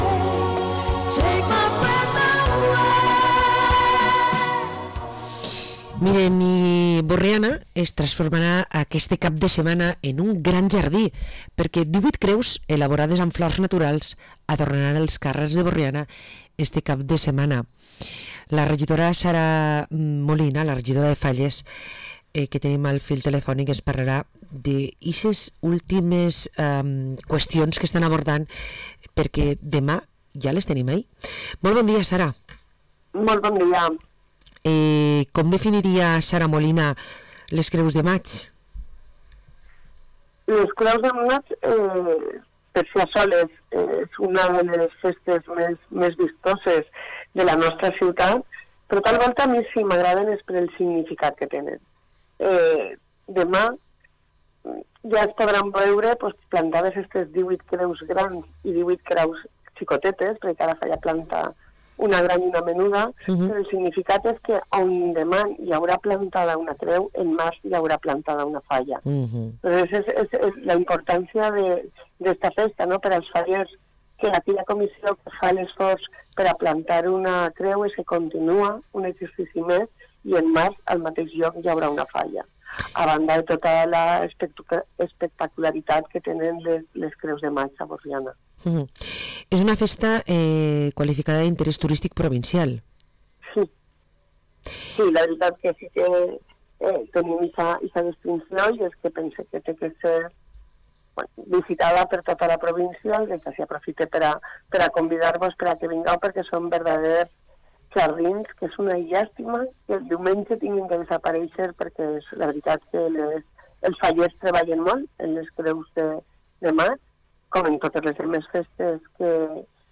Entrevista a Sara Molina, regidora de Falles de Borriana